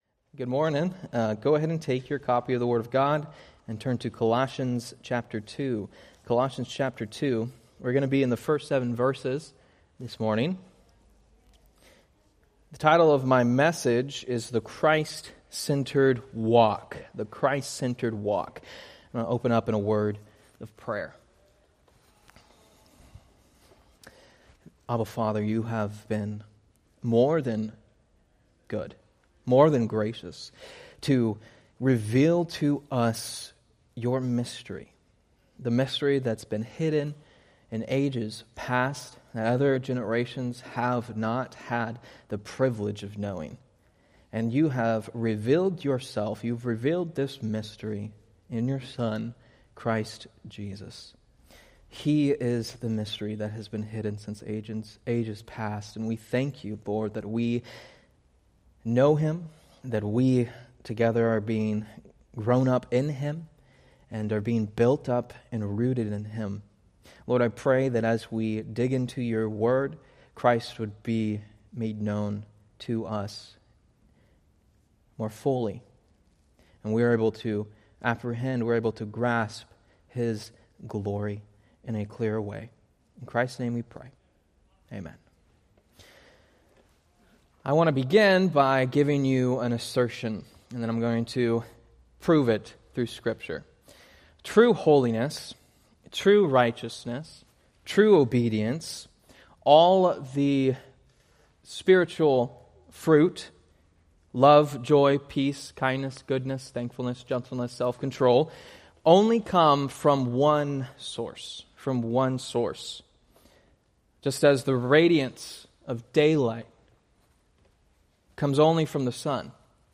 Date: Oct 26, 2025 Series: Various Sunday School Grouping: Sunday School (Adult) More: Download MP3